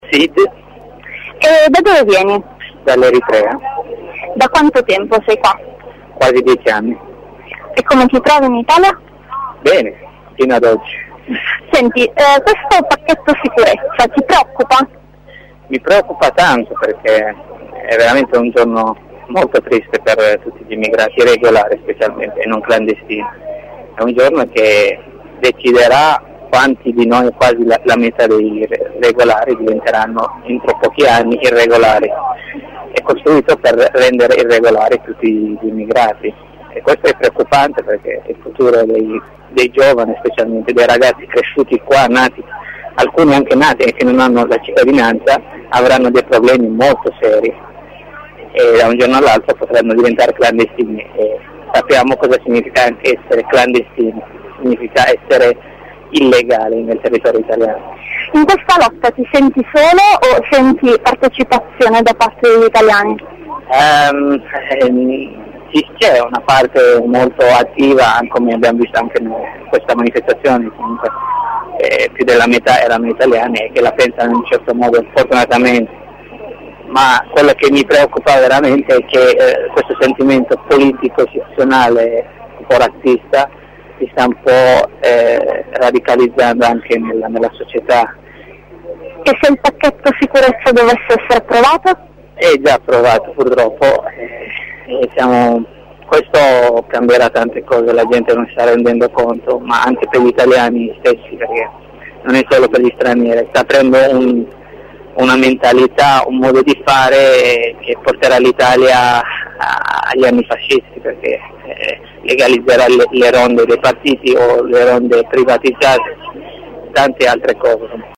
voci-presidio_09_05_13